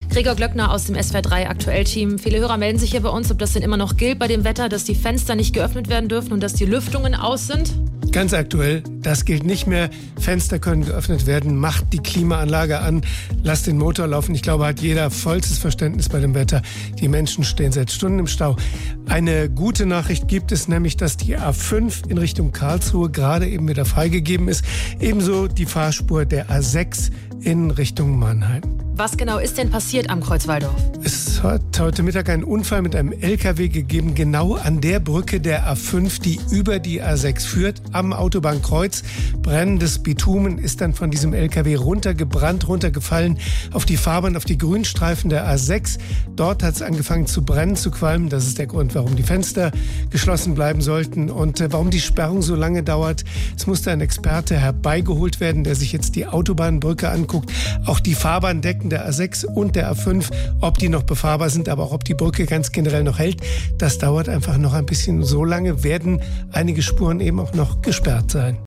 Wir halten euch auch in den Verkehrsnachrichten auf dem Laufenden:
Nachrichten Brand und lage Staus auf der A5 und A6 – das ist passiert